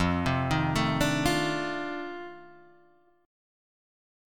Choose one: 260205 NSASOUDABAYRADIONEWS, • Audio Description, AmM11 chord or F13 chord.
F13 chord